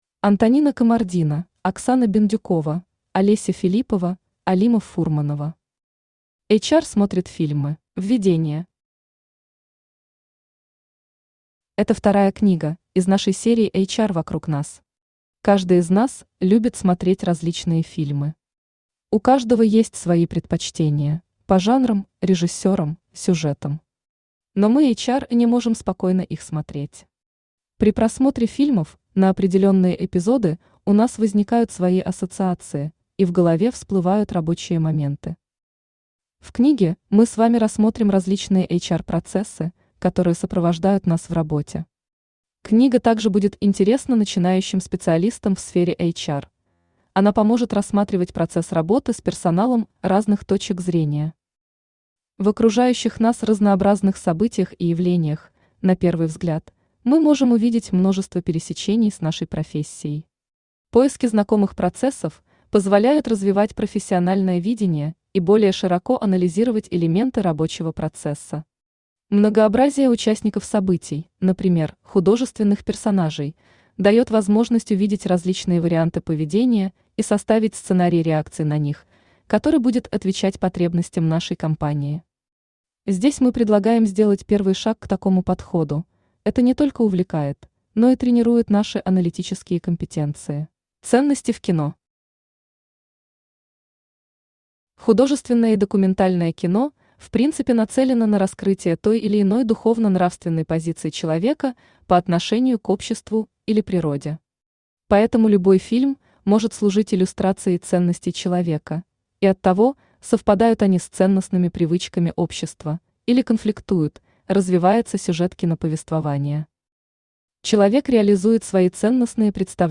Аудиокнига HR смотрит фильмы | Библиотека аудиокниг
Aудиокнига HR смотрит фильмы Автор Олеся Филиппова Читает аудиокнигу Авточтец ЛитРес.